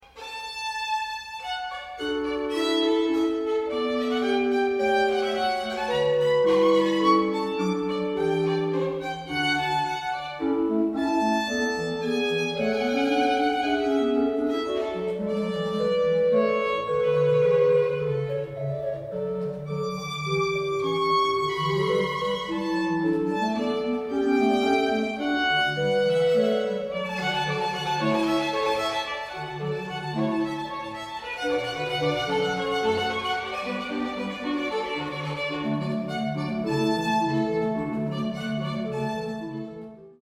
Klassische Kirchenkonzerte
Der Programmschwerpunkt ihrer Konzerte liegt auf Barockmusik, insbesondere von J.S. Bach und G.F. Händel, für Violine und Orgel sowie auf gesungenen Gebeten und Werken anderer Komponisten wie F. Mendelssohn, W.A. Mozart und J. Rheinberger.